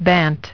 Транскрипция и произношение слова "bant" в британском и американском вариантах.